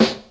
• 00's Rap Snare One Shot D Key 05.wav
Royality free snare drum sample tuned to the D note. Loudest frequency: 1244Hz
00s-rap-snare-one-shot-d-key-05-nUB.wav